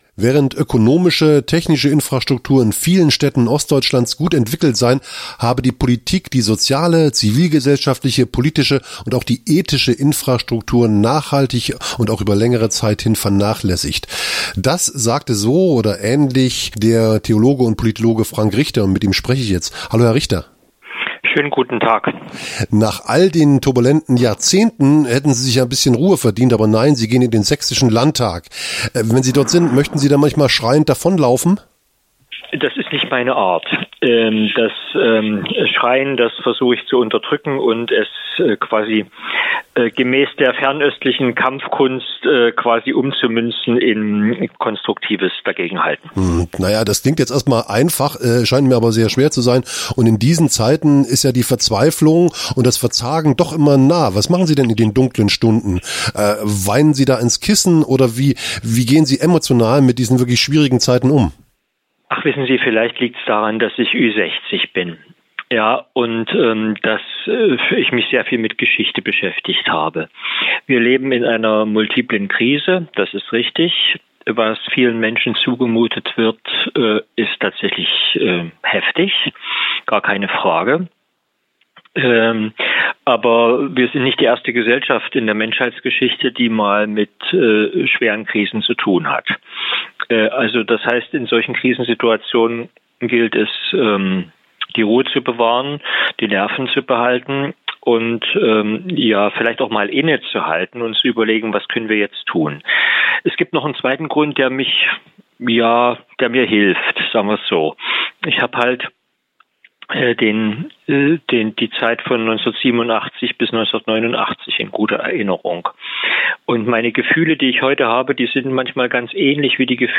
Aktionstage � Menschen, Medien, Meinungsbildung I Ein Rückblick mit Mitschnitt
Fünf Gespräche und viele offene Fragen.
> Download Die Aktionstage � Menschen, Medien, Meinungsbildung fanden vom 13. bis 15. Februar 2024 in Erfurt statt.